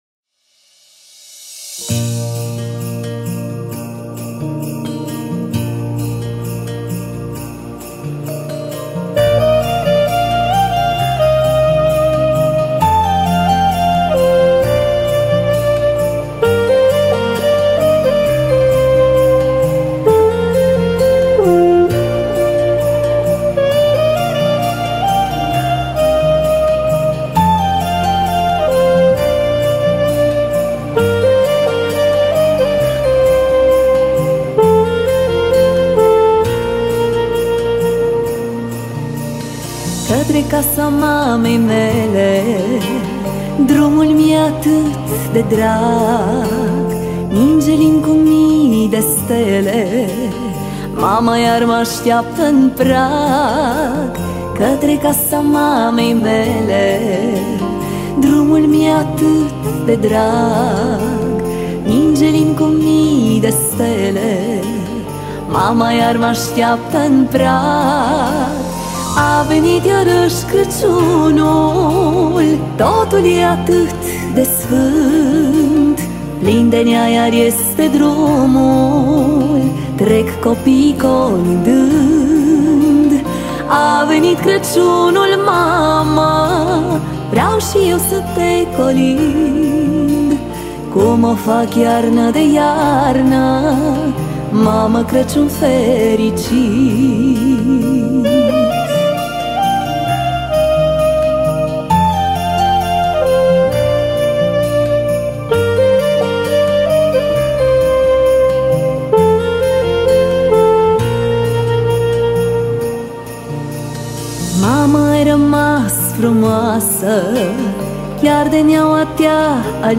Data: 13.10.2024  Colinde Craciun Hits: 0